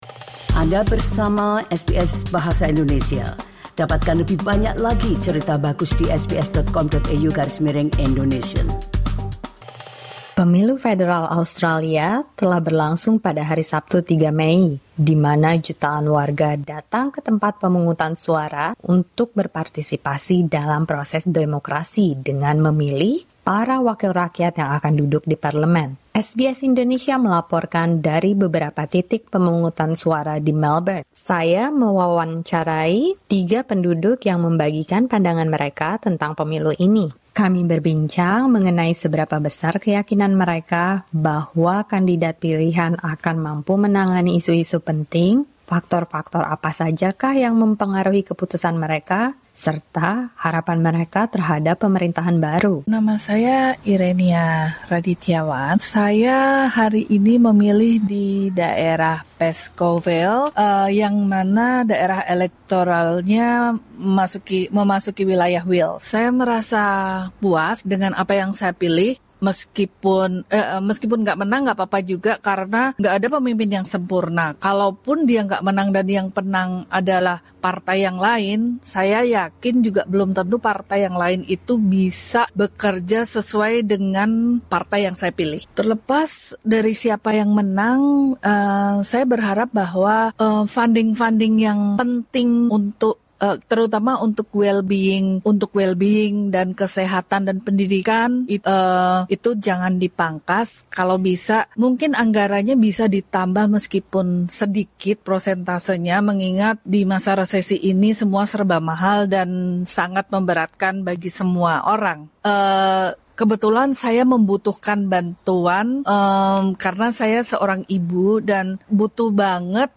Dengarkan Warga Australia Refleksikan Pertimbangan Utama saat Tentukan Pilihan dalam Pemilu SBS Indonesian 06:32 Indonesian SBS Indonesian berbicara dengan tiga warga Australia di tiga wilayah pemilihan yang berbeda di Melbourne yang berhak memberikan suara dalam Pemilu Federal 2025. Mahalnya biaya hidup dan tambahan subsidi untuk childcare termasuk faktor-faktor utama yang dipertimbangkan dalam menentukan pilihan politik mereka. Para pemilih ini juga menyampaikan harapan mereka terhadap pemerintah mendatang.